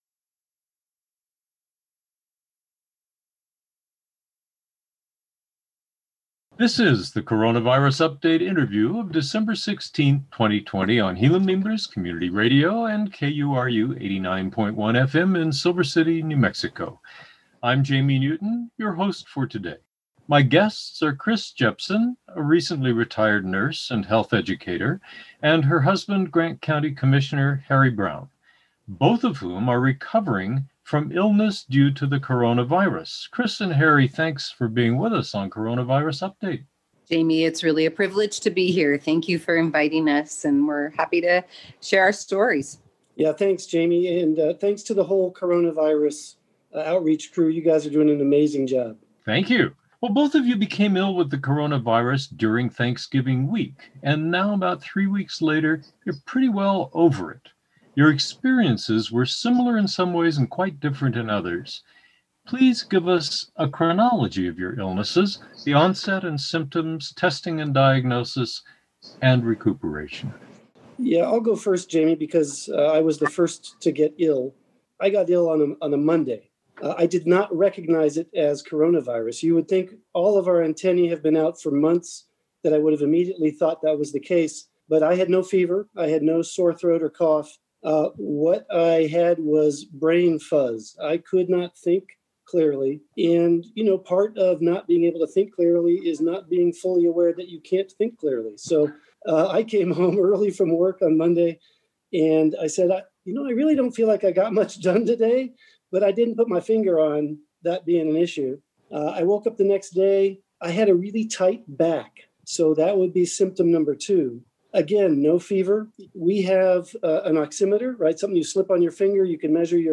Coronavirus Update is broadcast live on Gila/Mimbres Community Radio 89.1 FM Silver City on Wednesdays at Noon and rebroadcast Wednesdays at 6 pm and Thursdays at 8 am
interview